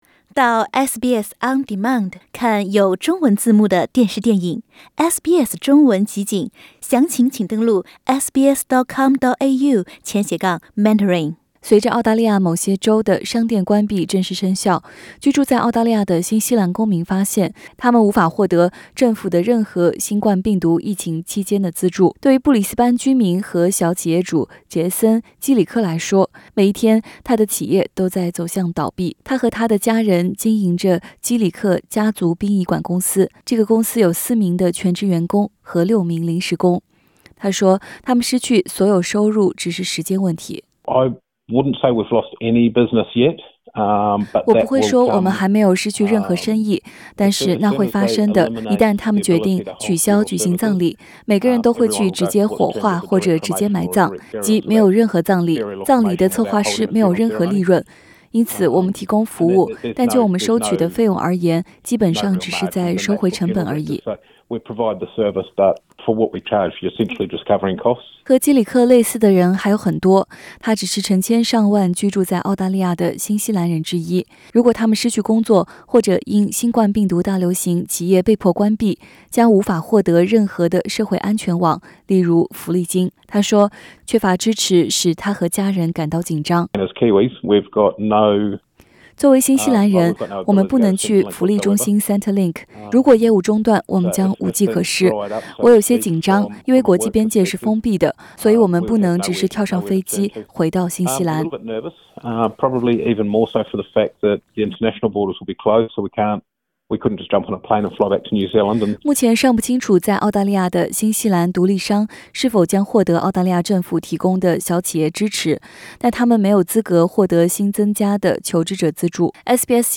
【COVID-19报道】在澳新西兰企业主面临困境，呼吁能获得政府支持